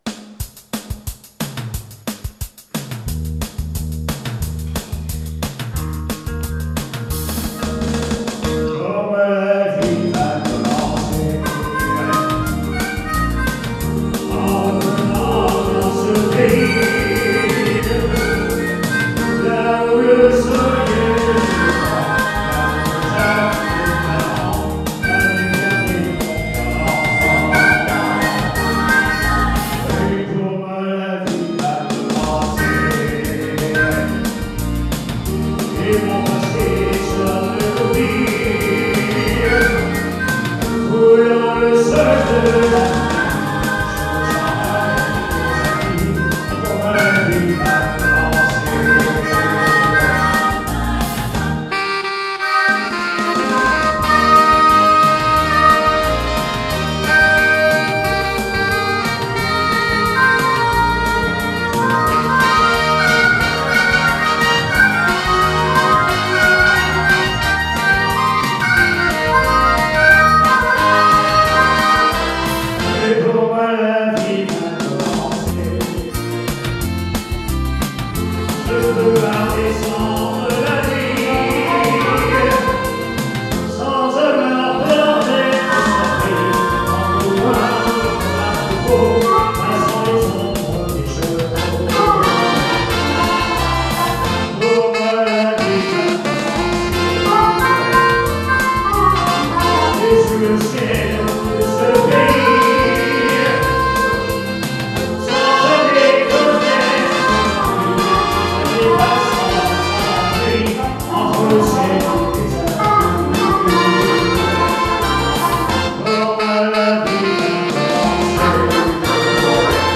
SOIREES BLUES-ROCK RETROSPECTIVE
DUO CHANT/HARMONICA
soiree bistrot d'oliv - 14-12-19